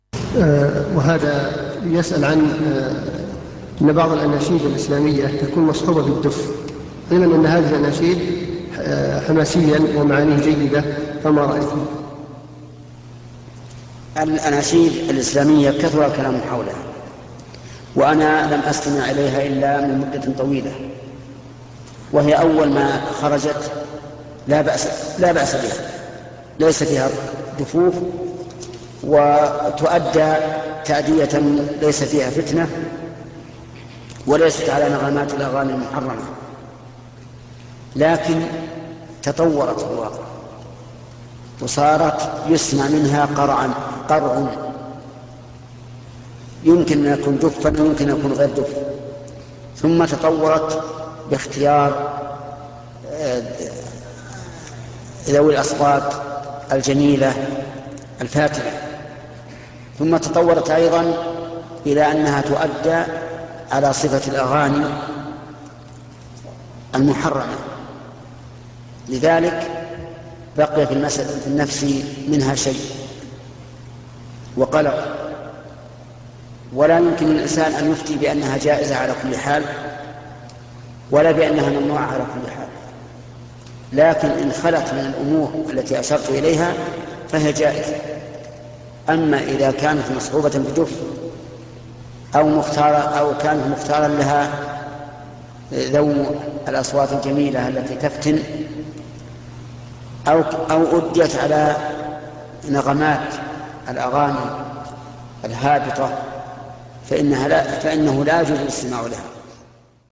Audioaufnahme des Schaykhes, al-Liqâ’ ash-Schahri (11).